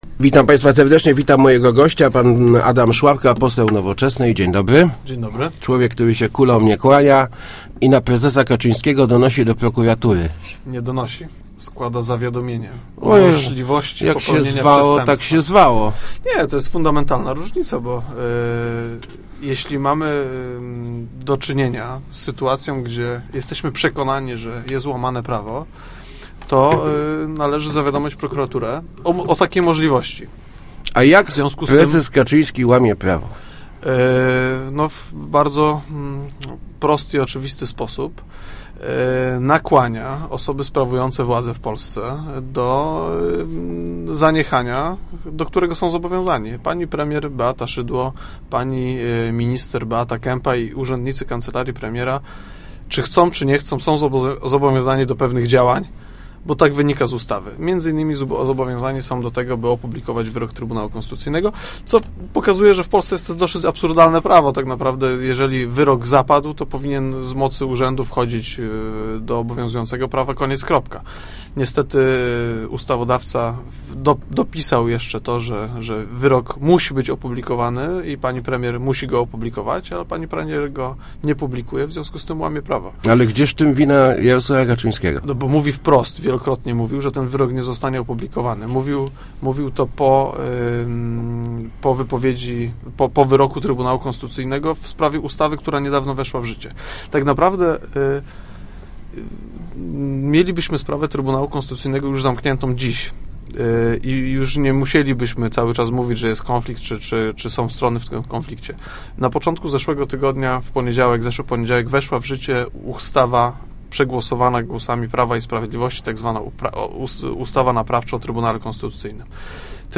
11 wrze�nia mamy konwencj�, na której zaprezentujemy nasz program - mówi� w Rozmowach Elki pose� Adam Sz�apka (Nowoczesna). Szczegó�ów nie chcia� zdradza�, jednak maj� si� w nim znale�� mi�dzy innymi wzmocnienie samorz�dów i korzystne rozwi�zania dla przedsi�biorców, takie jak niskie i proste podatki.